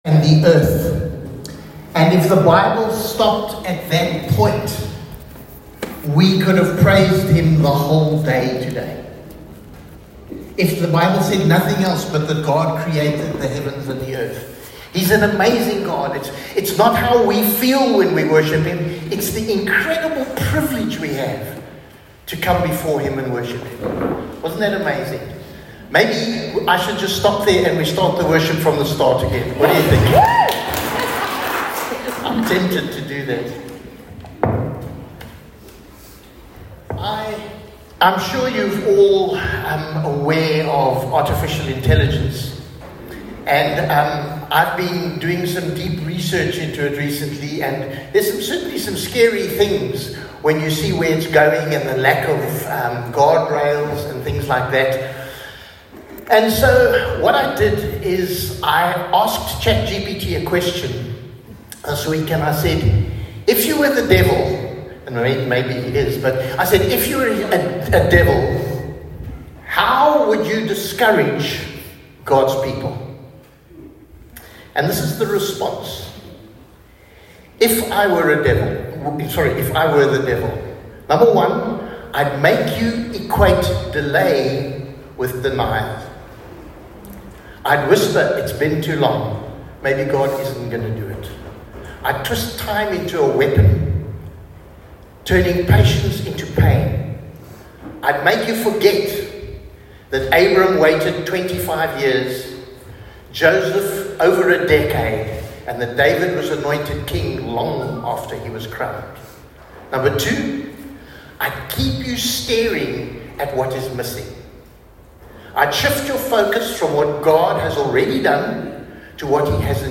Sunday Service – 19 October
Sermons